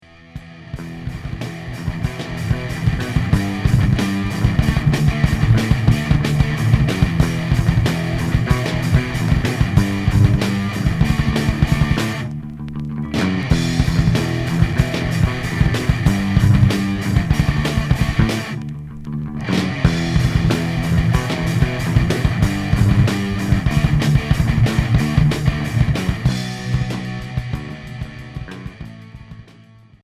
Emo core